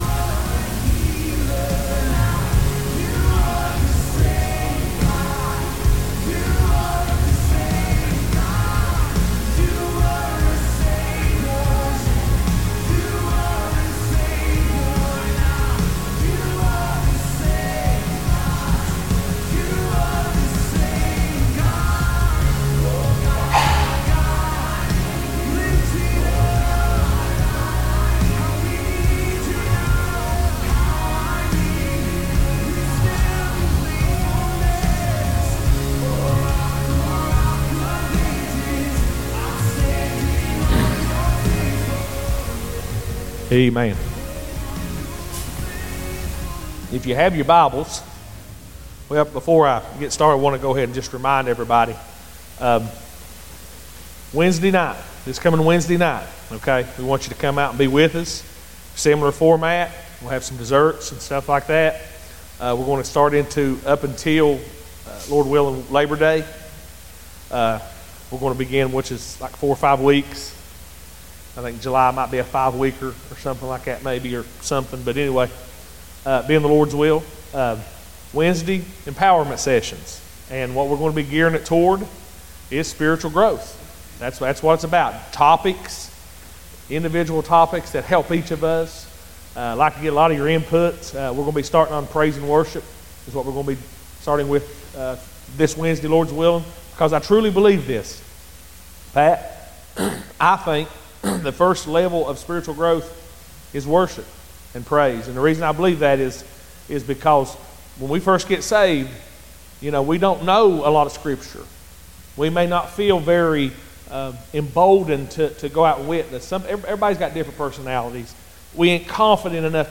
Sundayn Morning Teaching